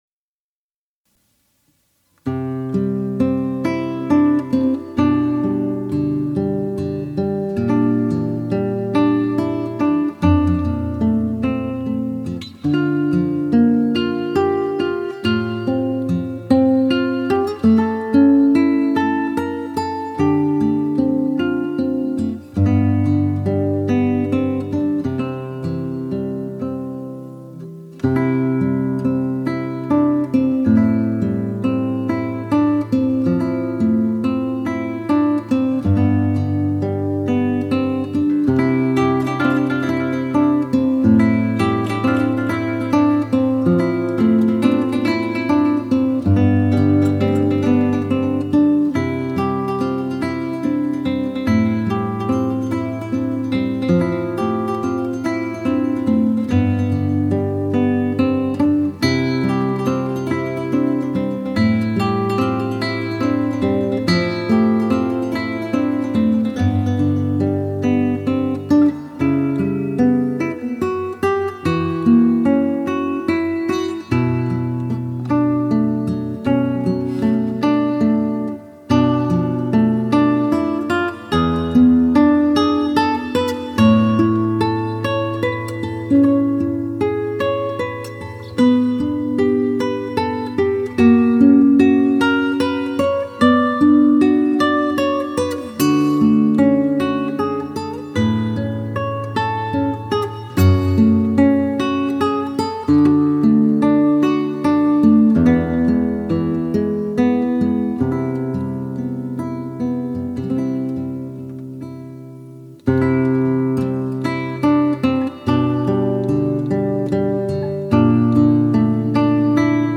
Genre Meditaciones Guiadas